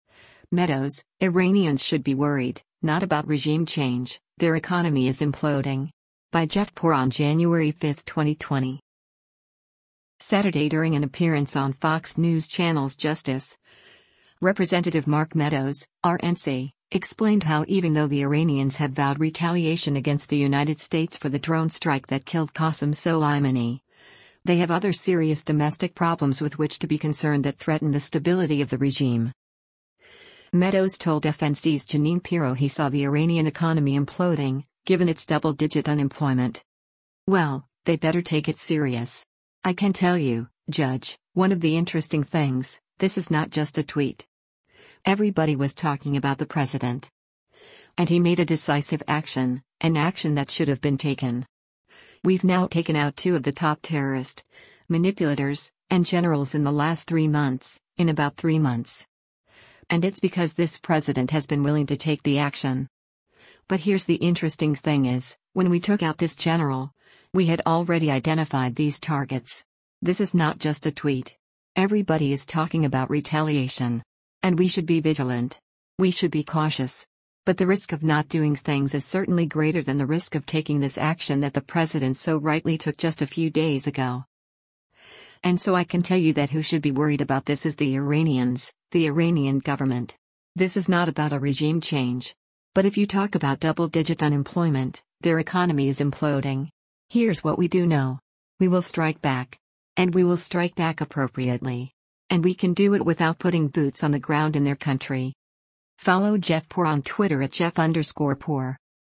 Saturday during an appearance on Fox News Channel’s “Justice,” Rep. Mark Meadows (R-NC) explained how even though the Iranians have vowed retaliation against the United States for the drone strike that killed Qasam Soleimani, they have other serious domestic problems with which to be concerned that threaten the stability of the regime.